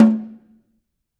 Snare2-HitNS_v6_rr2_Sum.wav